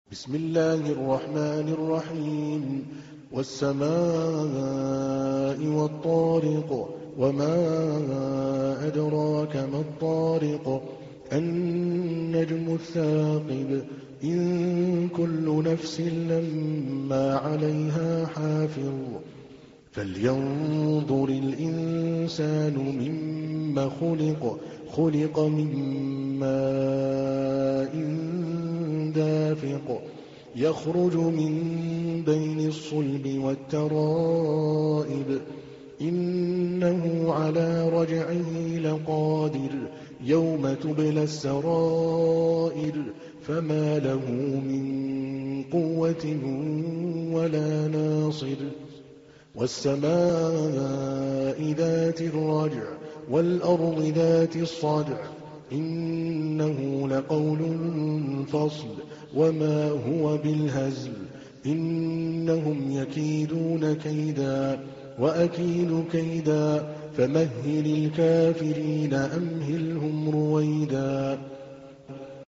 تحميل : 86. سورة الطارق / القارئ عادل الكلباني / القرآن الكريم / موقع يا حسين